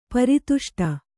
♪ pari tuṣṭa